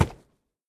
minecraft / sounds / mob / horse / wood2.ogg
wood2.ogg